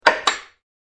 时钟嘀答声：mz22
时钟嘀答声 (mz22.wav)